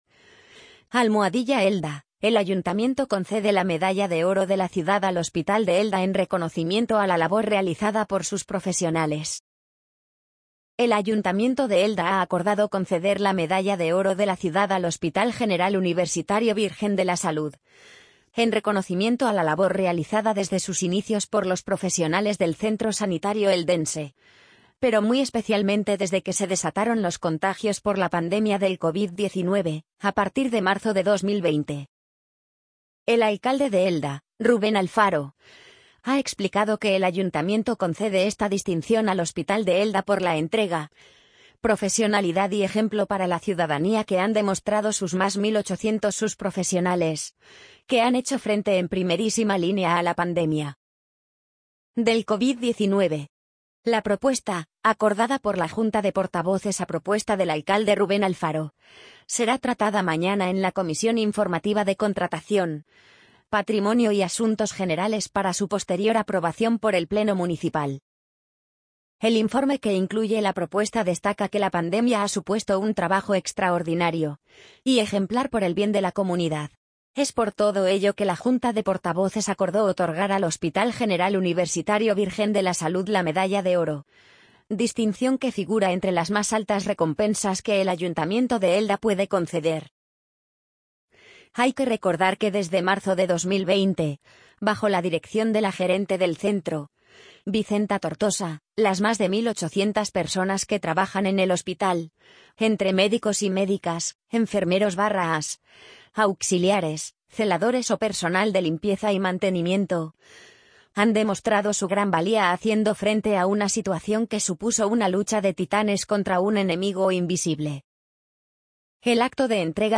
amazon_polly_50803.mp3